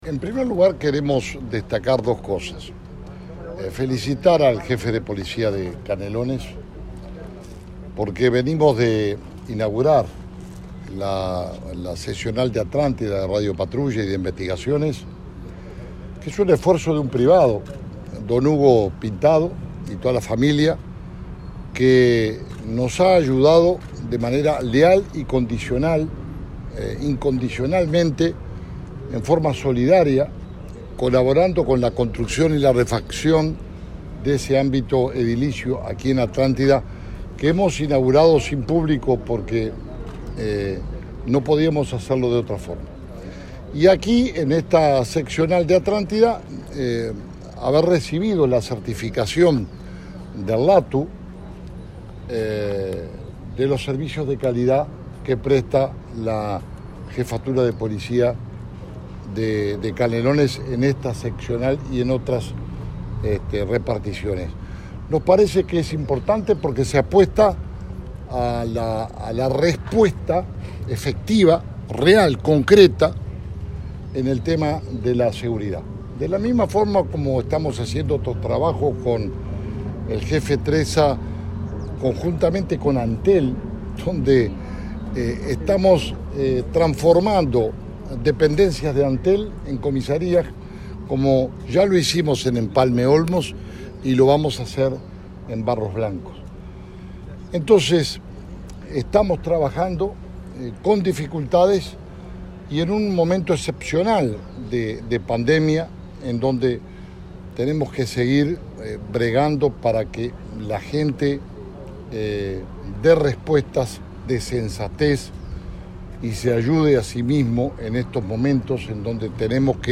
El Ministerio del Interior reinauguró las Bases Este de la Unidad de Respuesta Policial de Canelones y del Área de Investigaciones, en Atlántida, en un acto desarrollado el viernes 11 con la presencia del titular de esa cartera, Jorge Larrañaga. En cada área, trabajarán 20 efectivos policiales desde el peaje de Pando hasta el límite con Maldonado.